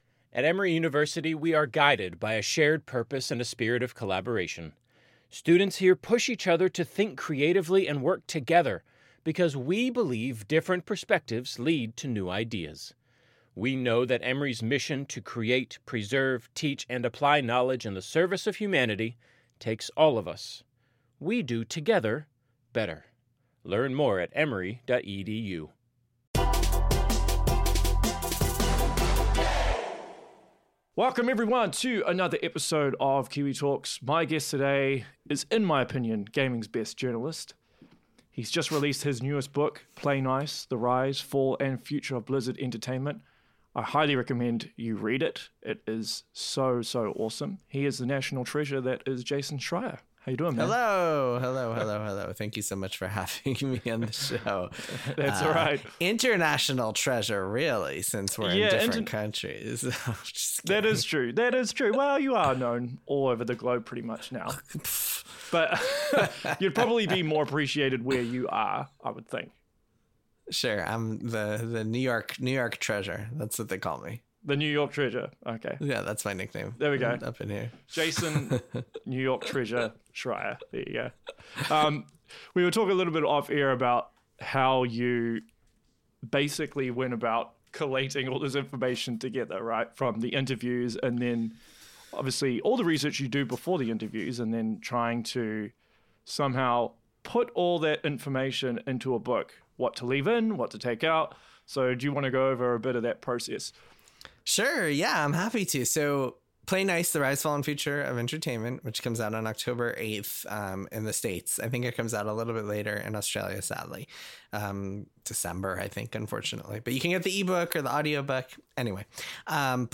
#173 - Jason Schreier Interview (Play Nice: The Rise, Fall & Future Of Blizzard Entertainment) ~ Kiwi Talkz Podcast